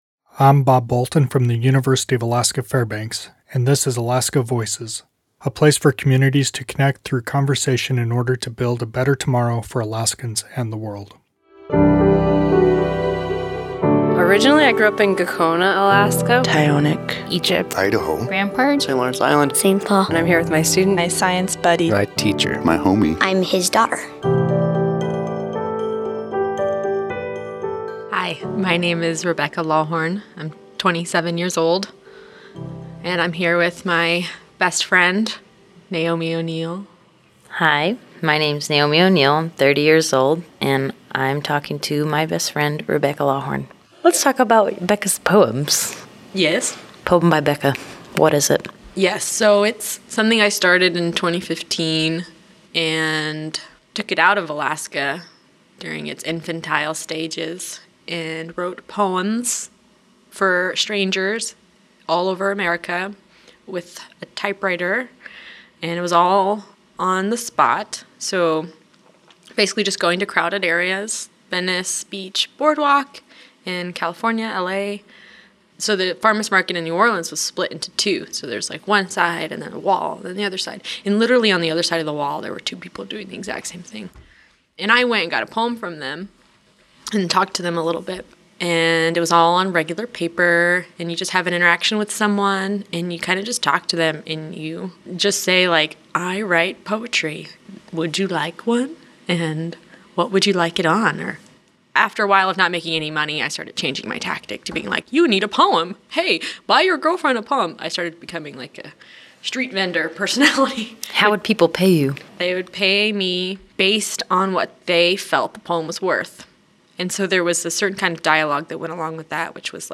2018 at the University of Alaska Fairbanks in Fairbanks, Alaska.
Music: “Arctic Evening” by Marcel du Preez